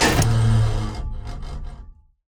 gun-turret-activate-04.ogg